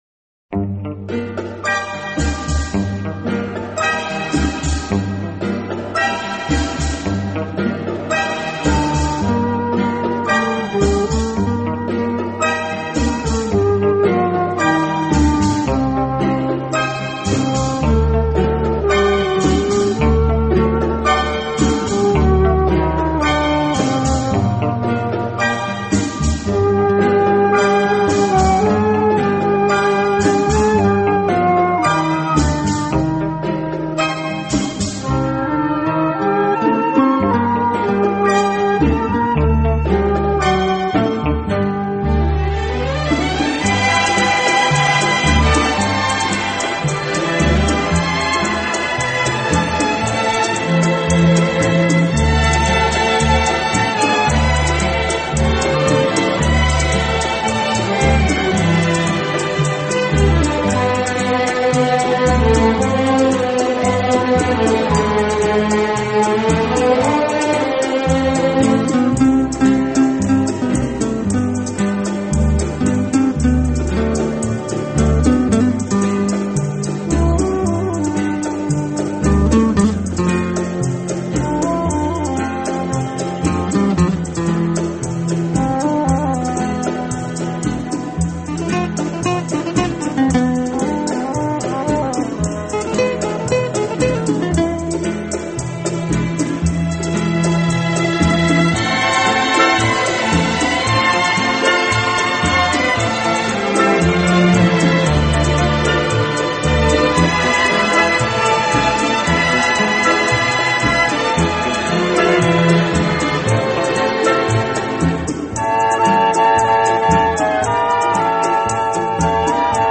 轻音乐
轻音乐专辑